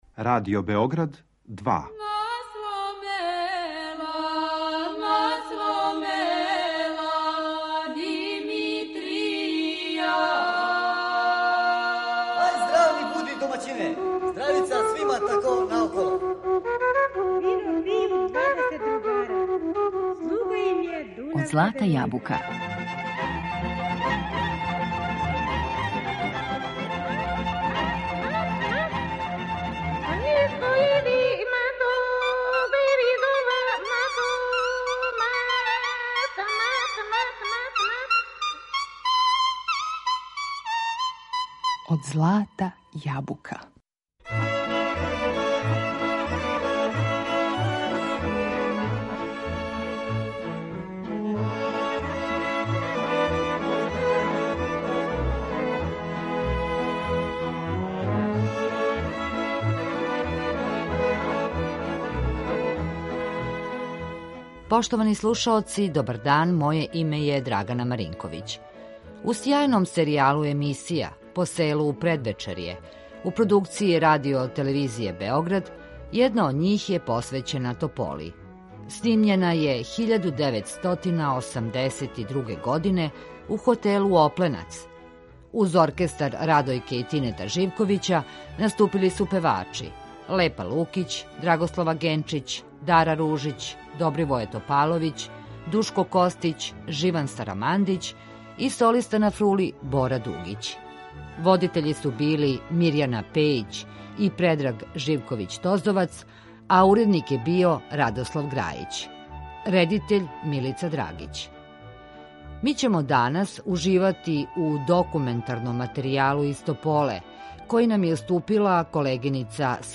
У серијалу емисија „Посело у предвечерје”, у продукцији Радио Телевизје Београд, једна од њих посвећена је Тополи, а снимљена је 1982. године.